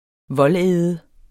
Udtale [ ˈvʌlˌεːðə ]